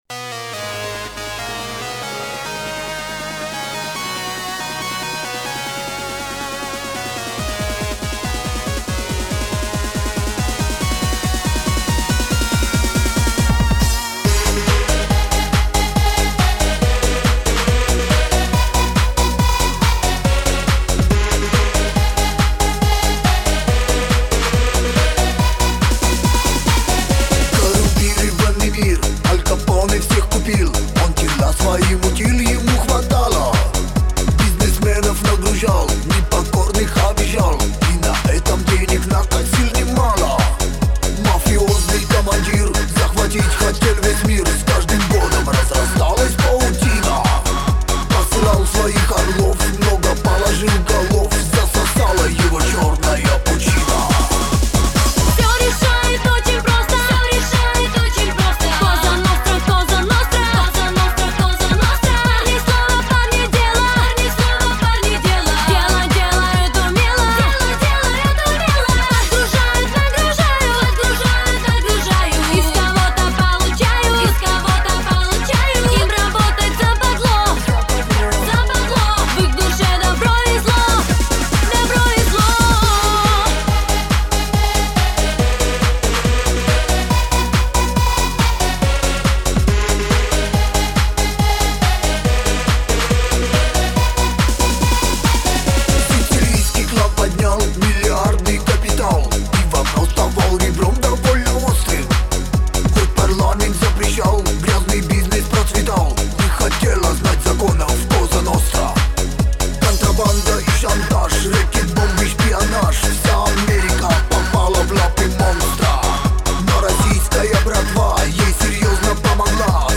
Жанр: Техно-Поп